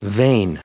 added pronounciation and merriam webster audio
1998_vain.ogg